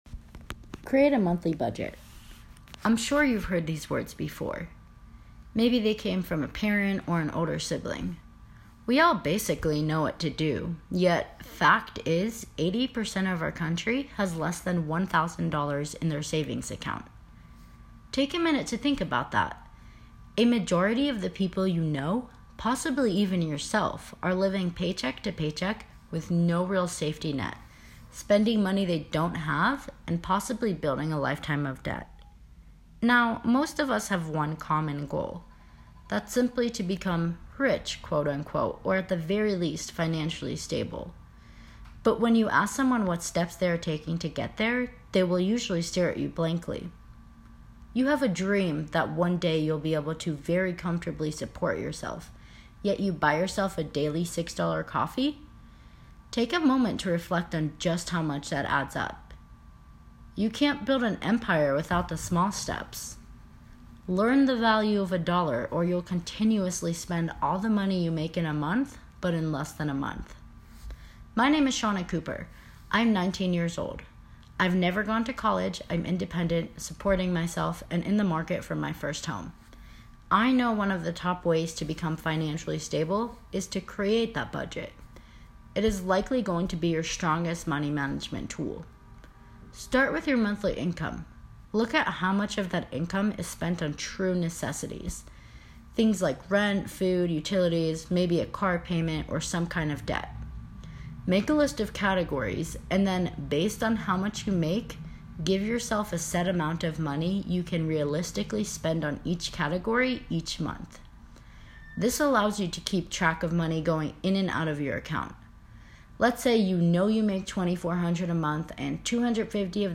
It features a real-life young adult and her journey to financial wellness.